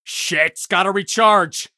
gun_jam_7.ogg